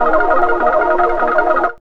0505L WETARP.wav